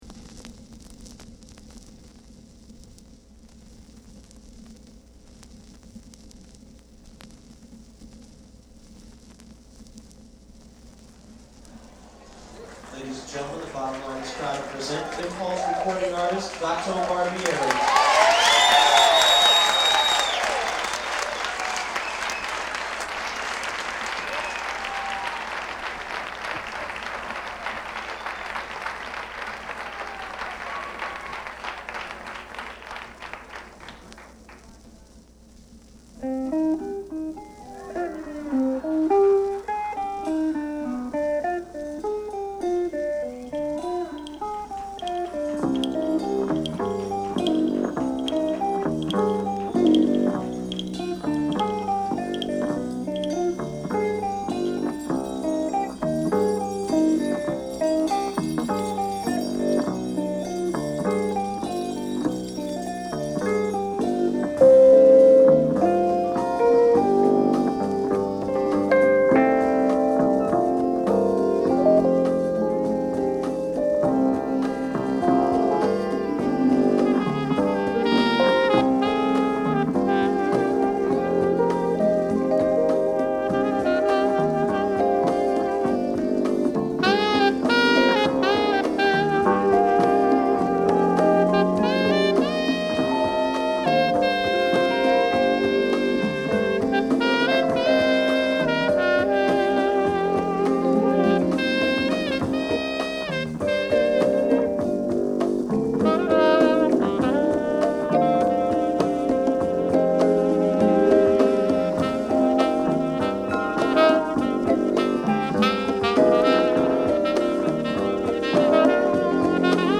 Latin Jazz Jacket
Fender Rhodes
哀愁と熱量が同居する。